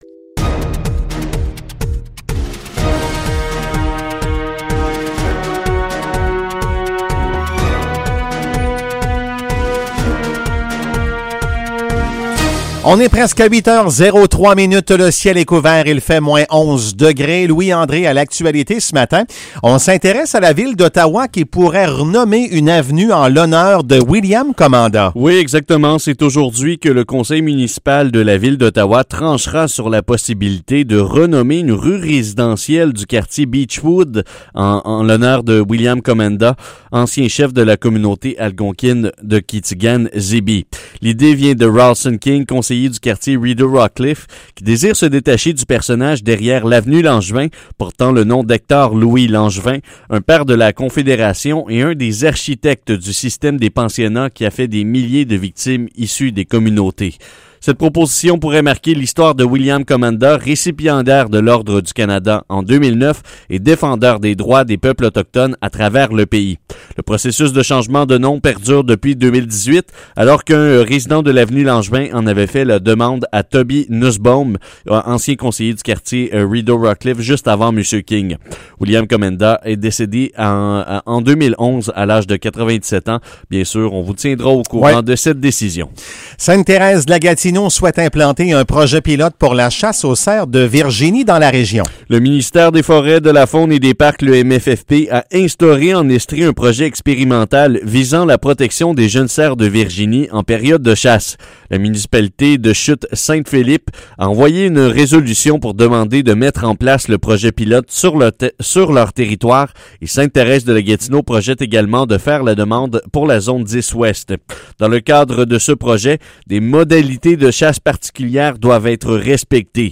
Nouvelles locales - 26 janvier 2021 - 8 h